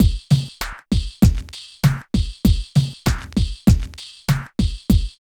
71 DRUM LP-L.wav